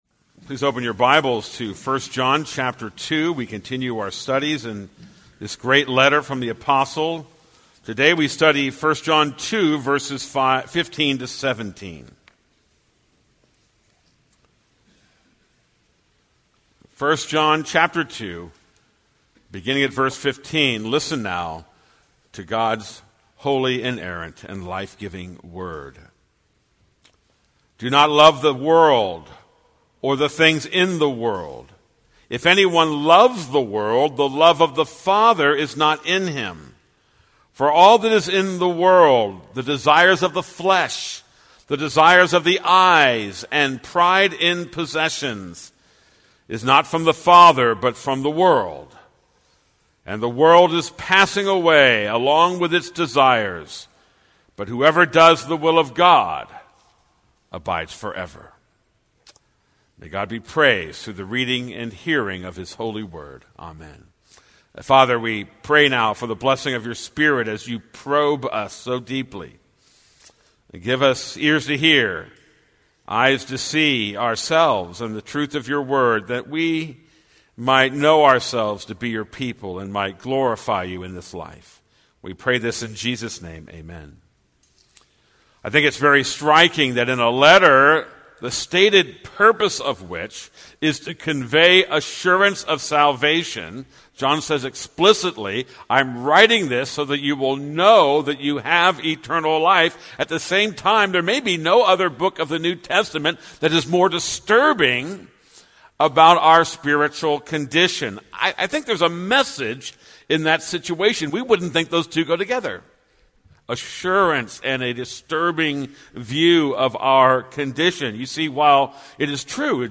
This is a sermon on 1 John 2:15-17.